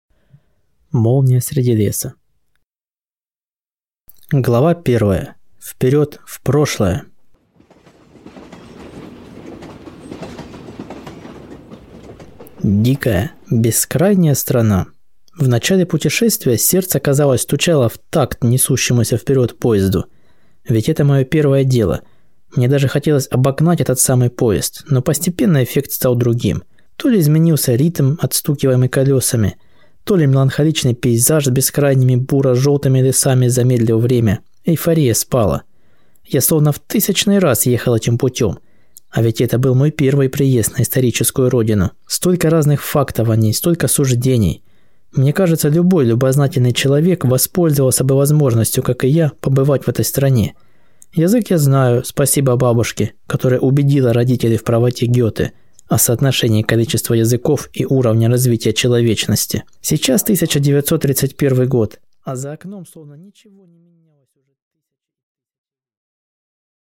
Аудиокнига Молния среди леса | Библиотека аудиокниг